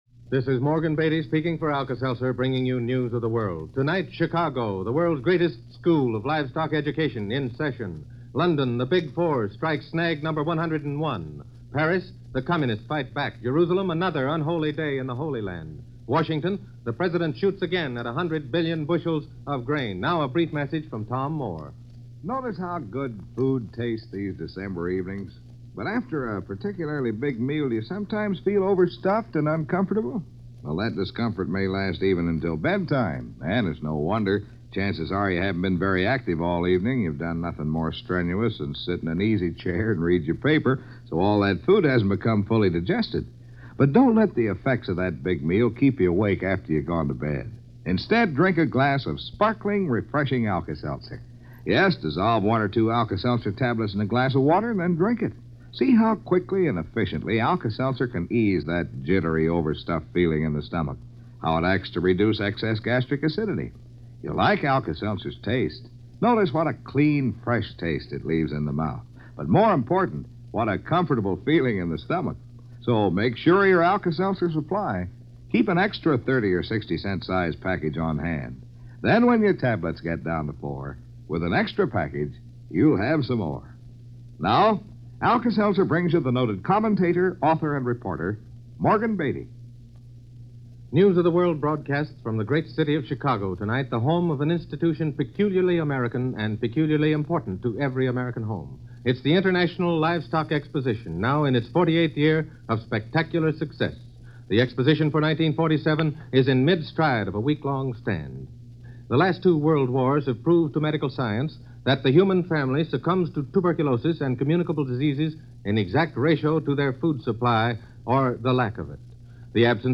December 4, 1947 – News Of The World – NBC Radio – Gordon Skene Sound Collection